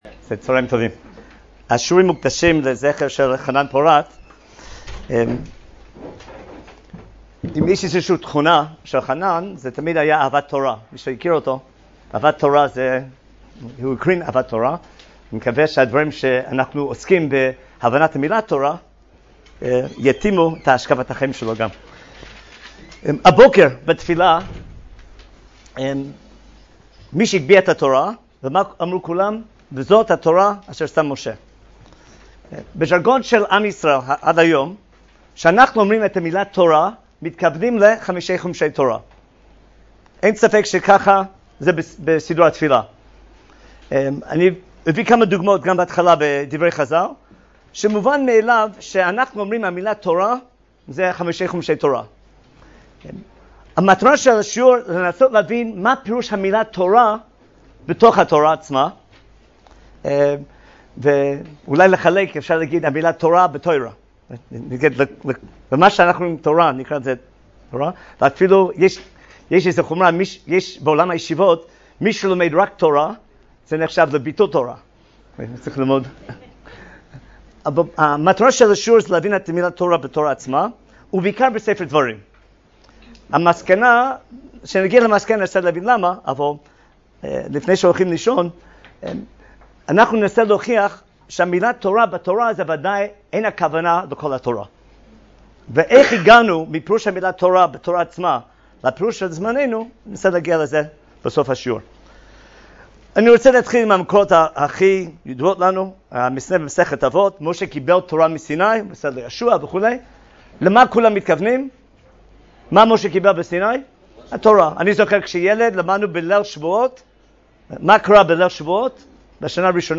השיעור באדיבות אתר התנ"ך וניתן במסגרת ימי העיון בתנ"ך של המכללה האקדמית הרצוג